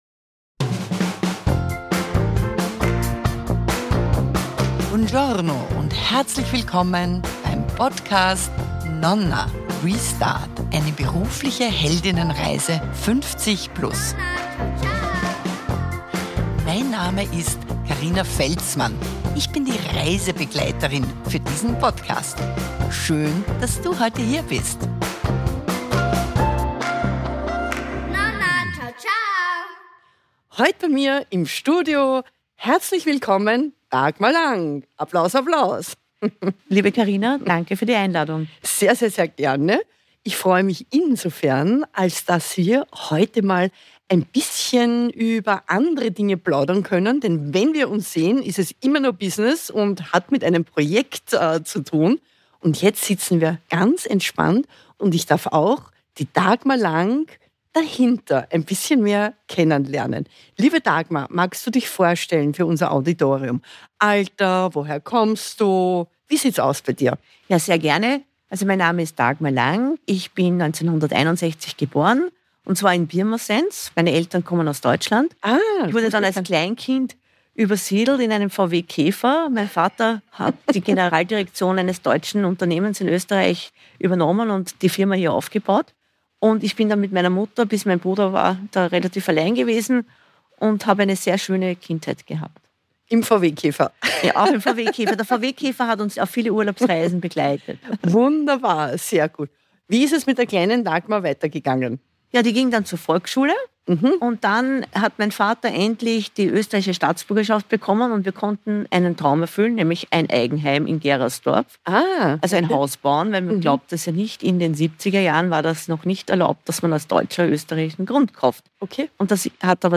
interviewt zur beruflichen Held*innenreise.